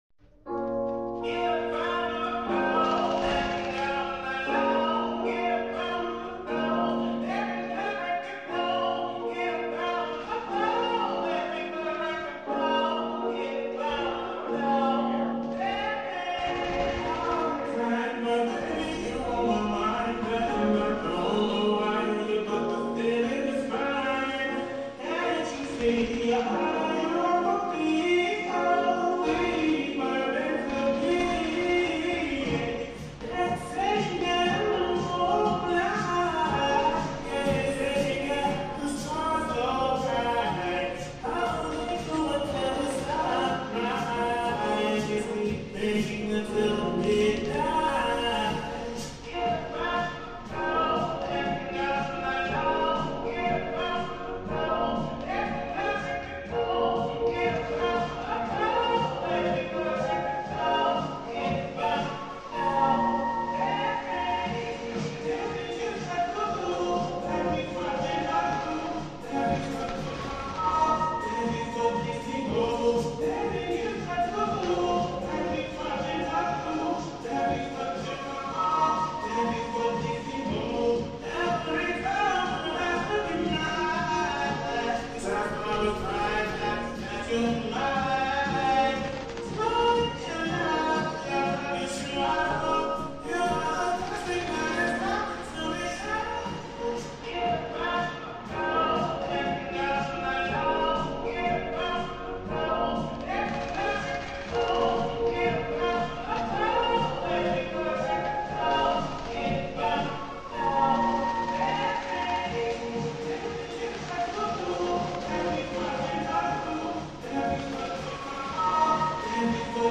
Classic weapons, fast-paced action pure nostalgia.